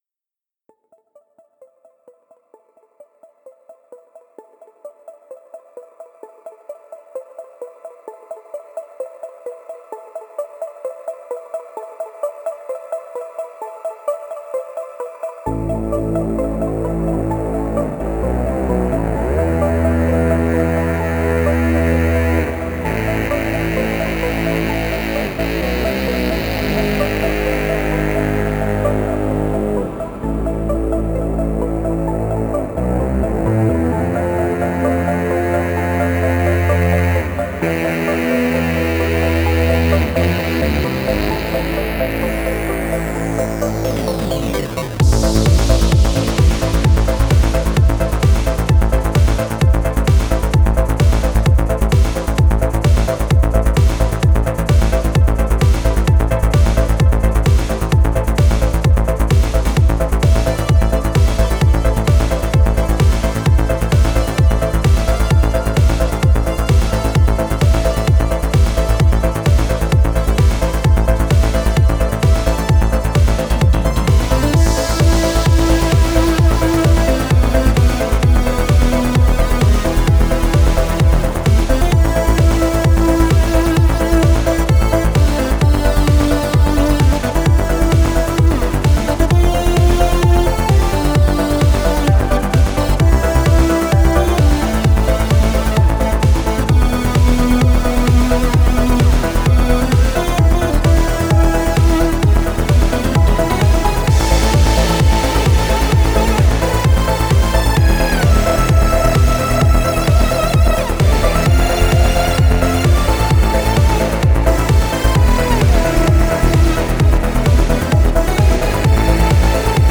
Genre: Synthwave
Style: Darksynth